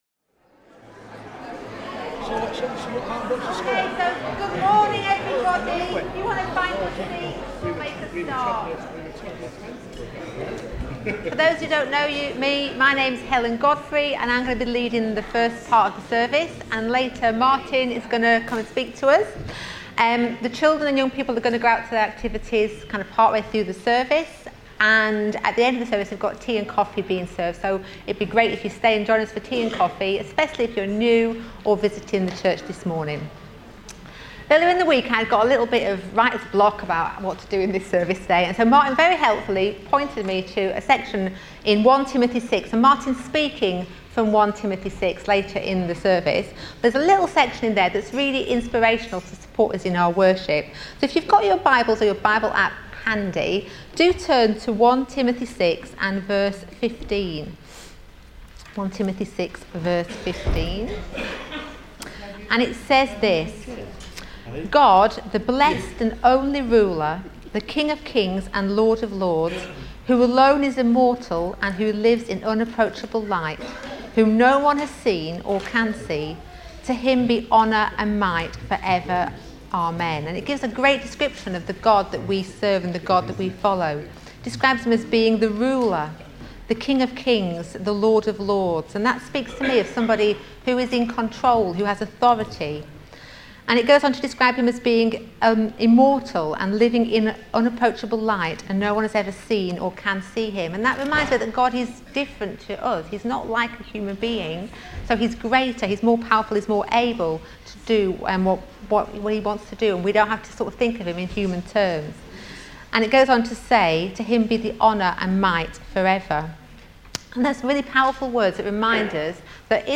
28 September 2025 – Morning Service